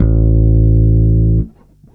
11-A1.wav